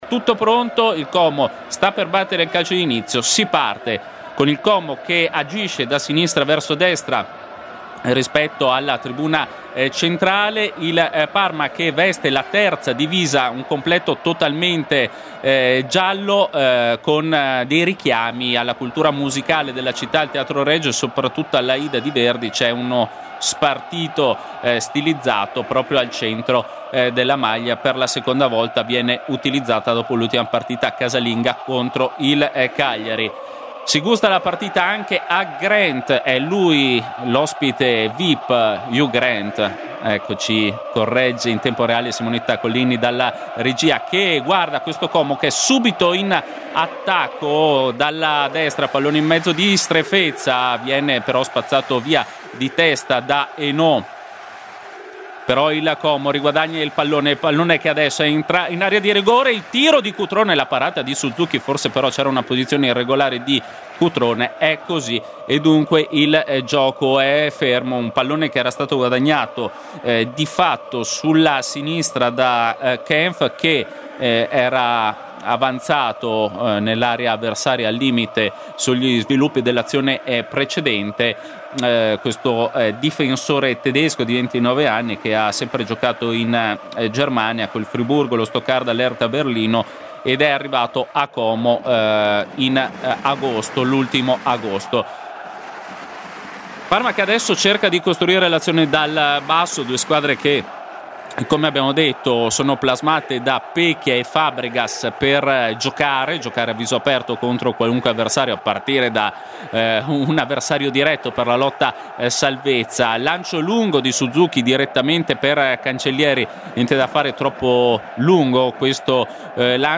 Sfida fra neopromosse allo stadio Sinigaglia. Si affrontano il Como, a 8 punti in classifica, e il Parma, a quota 6.
Radiocronache Parma Calcio Como - Parma - 1° tempo - 19 ottobre 2024 Oct 19 2024 | 00:46:46 1x 00:00 / 00:46:46 Subscribe Share RSS Feed Share Link Embed